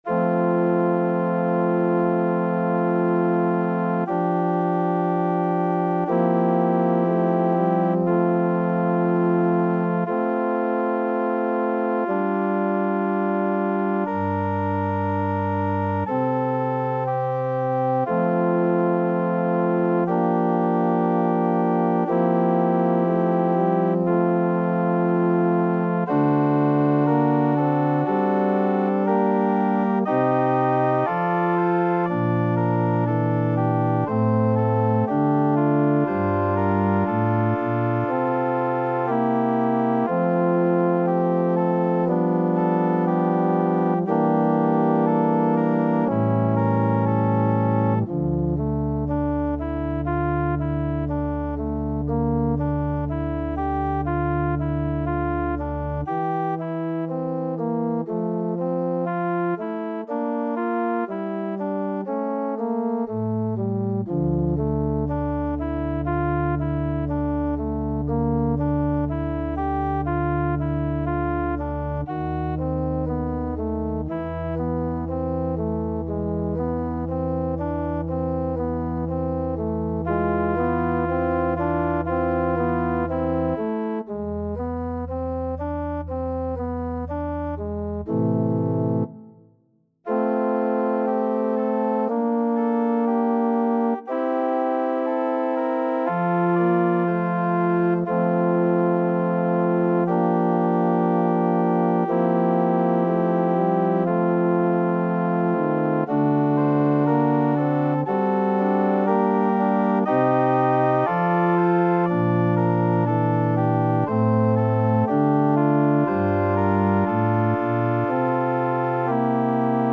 2/4 (View more 2/4 Music)